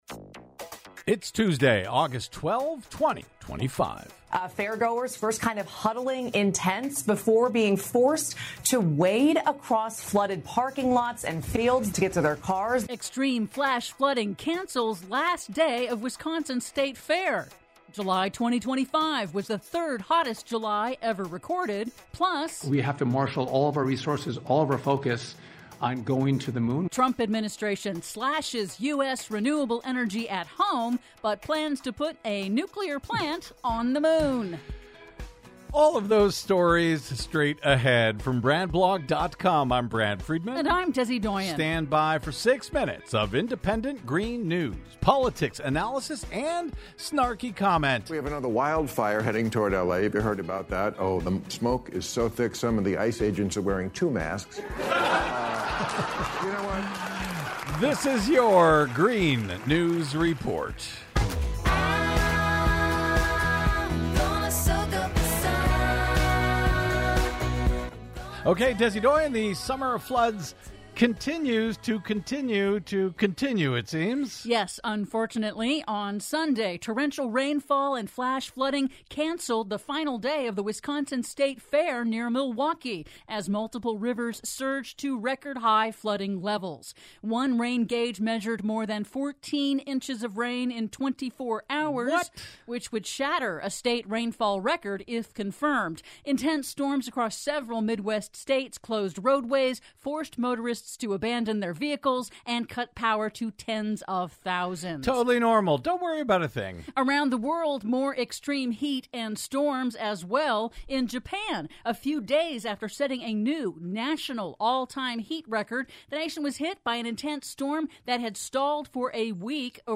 IN TODAY'S RADIO REPORT: Extreme flash flooding forces early cancellation of Wisconsin State Fair; July 2025 was the third hottest July ever recorded; PLUS: Trump Administration slashes U.S. renewable energy at home but plans to put a nuclear reactor on the Moon... All that and more in today's Green News Report!